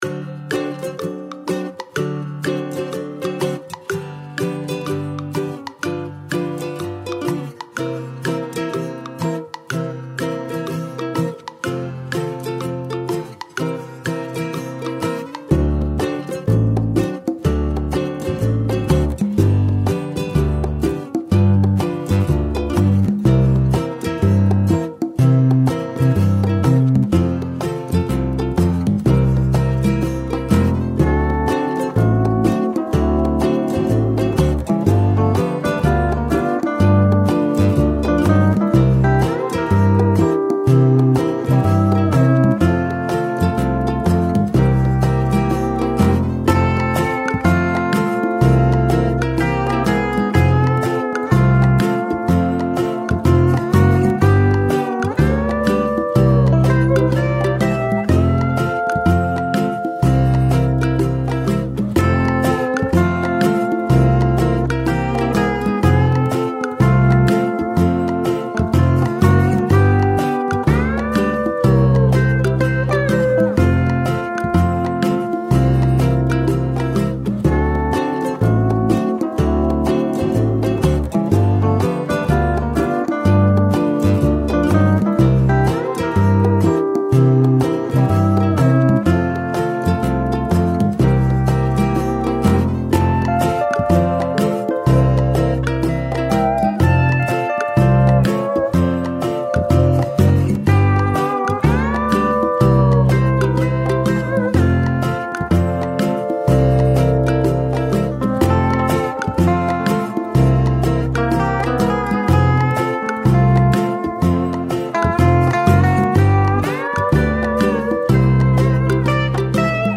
Largo [0-10] - - - -